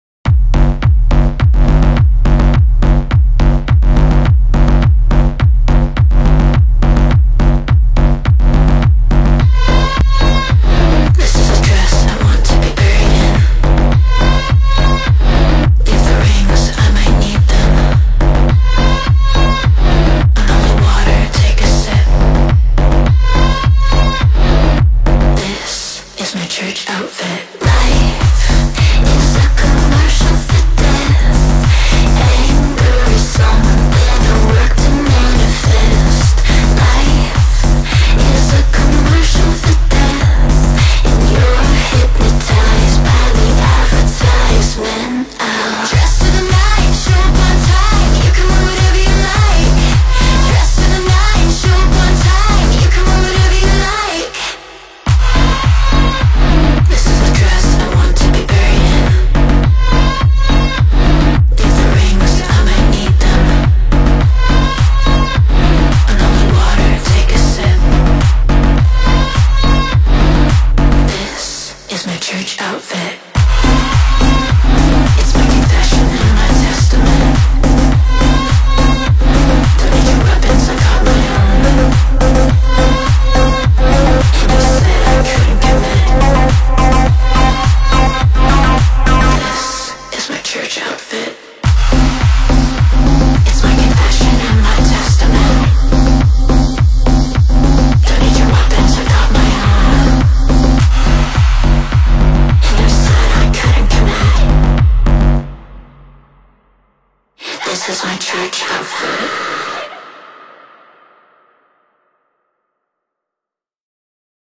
has a magnificent beat